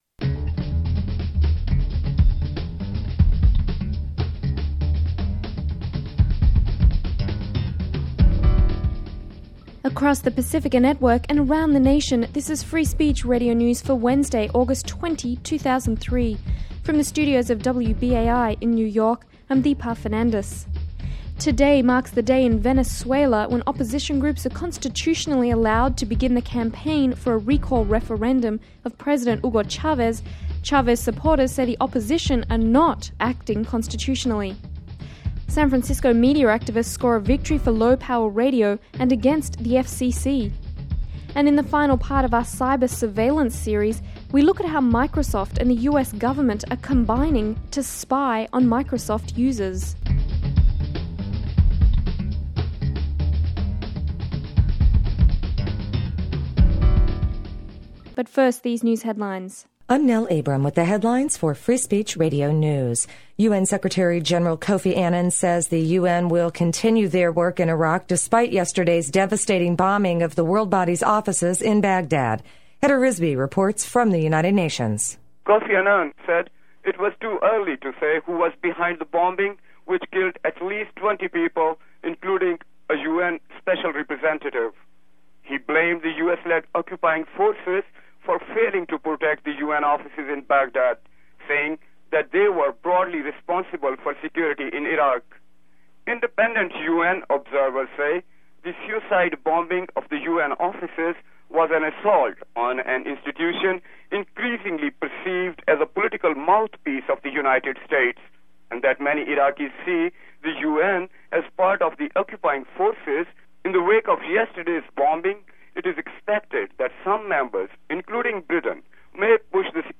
Listen to the headlines for Wed., Aug. 20, where FSRN features SFLR's historic victory for the lpfm movement. Check out comments by SFLR dj's, producers, and advocates.